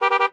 highway / oldcar / honk2.ogg
honk2.ogg